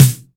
• Clean Snare Drum Sound C Key 32.wav
Royality free steel snare drum sample tuned to the C note. Loudest frequency: 2066Hz
clean-snare-drum-sound-c-key-32-jBr.wav